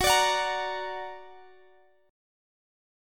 Gb+ Chord (page 3)
Listen to Gb+ strummed